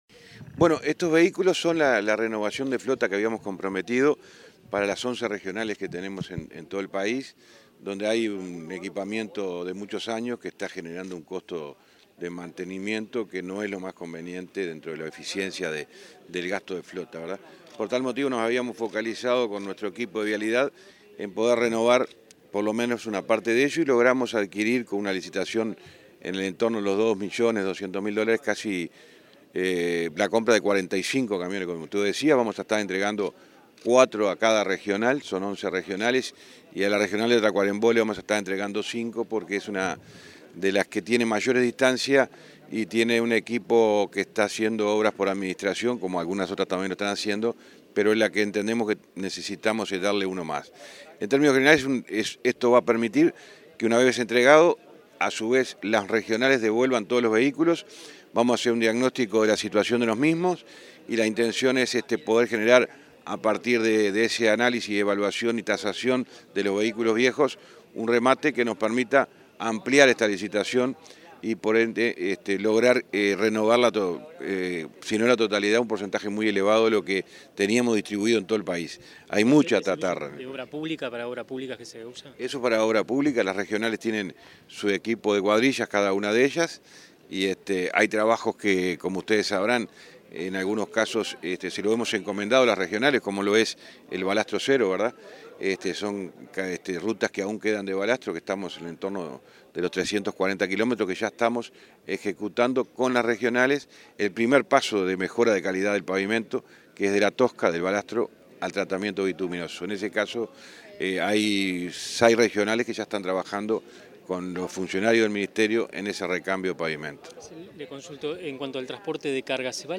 Declaraciones del ministro de Transporte y Obras Públicas, José Luis Falero
El ministro de Transporte, José Luis Falero, dialogó con la prensa en Montevideo sobre la entrega de 45 camiones para la Dirección Nacional de